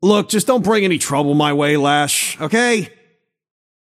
Shopkeeper voice line - Look, just don’t bring any trouble my way, Lash, okay?
Shopkeeper_hotdog_t4_lash_03.mp3